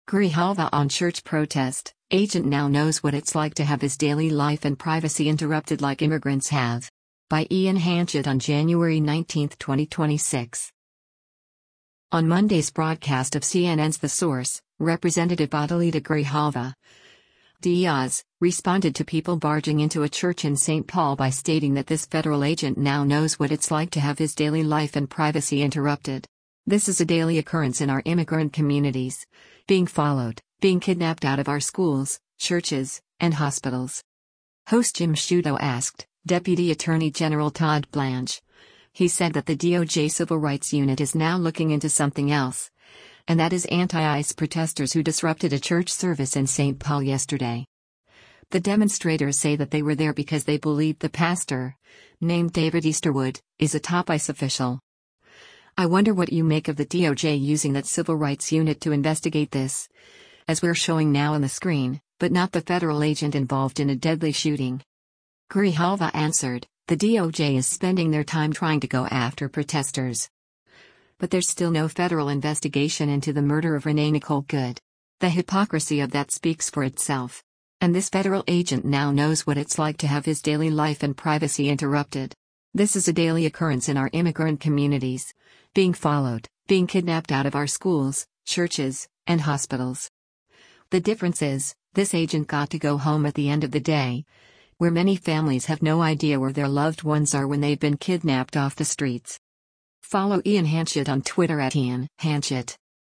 On Monday’s broadcast of CNN’s “The Source,” Rep. Adelita Grijalva (D-AZ) responded to people barging into a church in St. Paul by stating that “this federal agent now knows what it’s like to have his daily life and privacy interrupted. This is a daily occurrence in our immigrant communities, being followed, being kidnapped out of our schools, churches, and hospitals.”